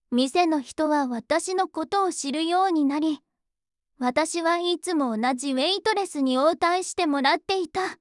voicevox-voice-corpus
voicevox-voice-corpus / ita-corpus /もち子さん_怒り /EMOTION100_043.wav